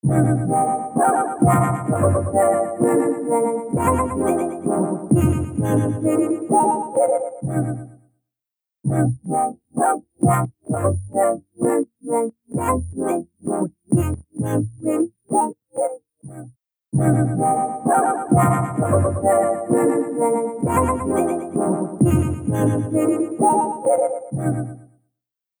H910 Harmonizer | Synth | Preset: Back to the 50s
H910-Harmonizer-Eventide-Synth-Poly-Back-to-the-50s.mp3